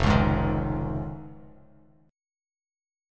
Fm7#5 chord